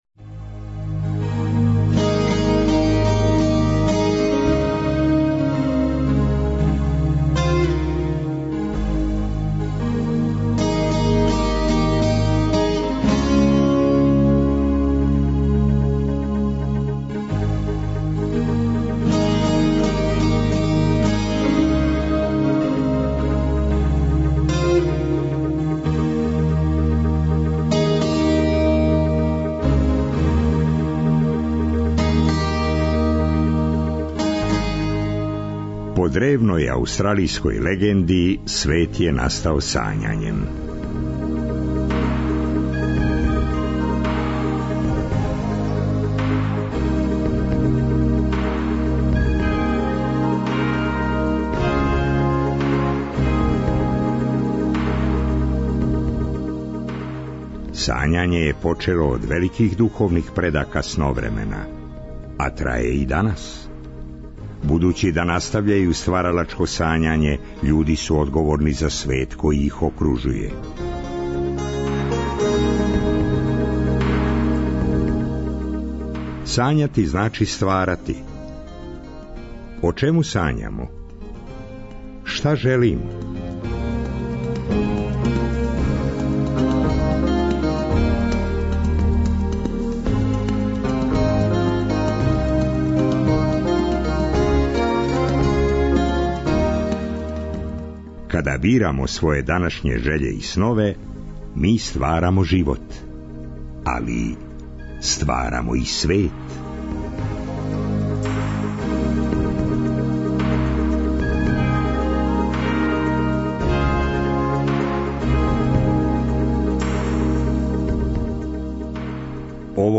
Четвртак, 11. септембар, сунчан дан у Неготину! Шетамо, разговарамо, дружимо се...